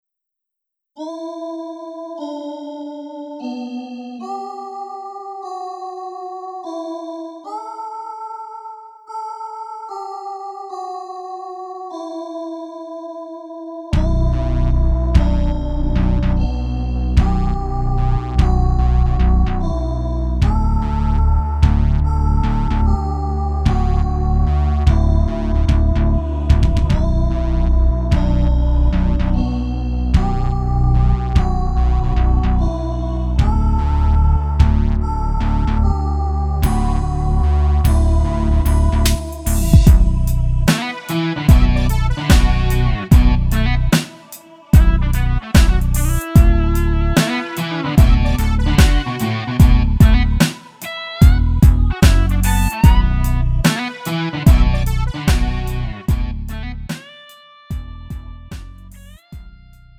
음정 원키 2:18
장르 구분 Lite MR